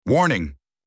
warning.mp3